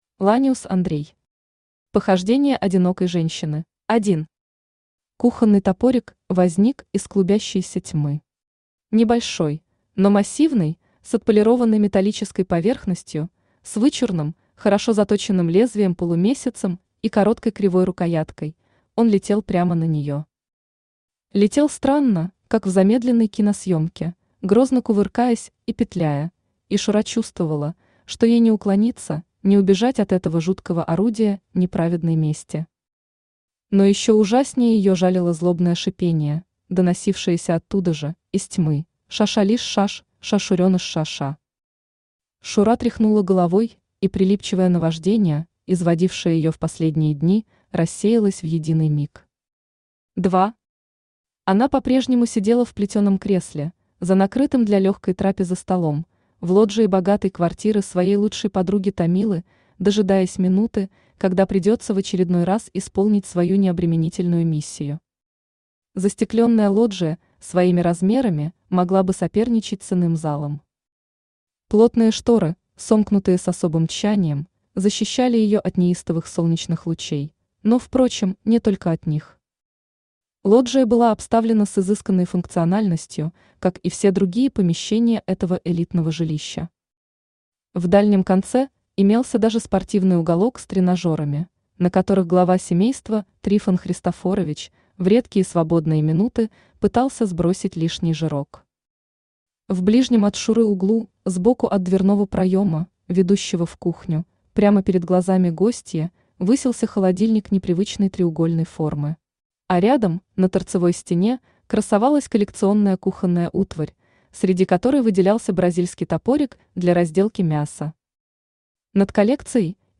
Аудиокнига Похождение одинокой женщины | Библиотека аудиокниг
Aудиокнига Похождение одинокой женщины Автор Ланиус Андрей Читает аудиокнигу Авточтец ЛитРес.